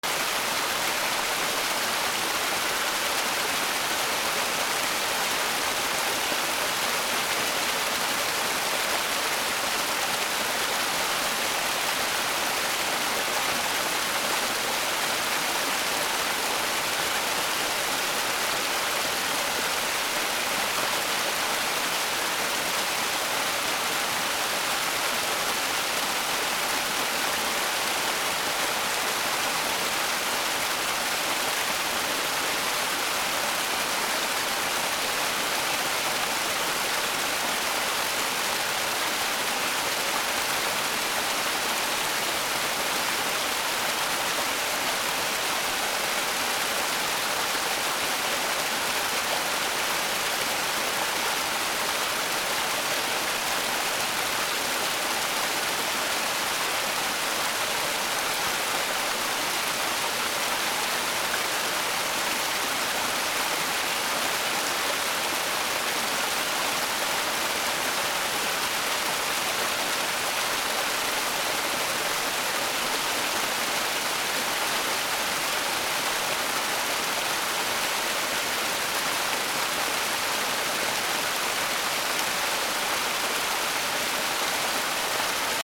1 清流 オン
/ B｜環境音(自然) / B-15 ｜水の流れ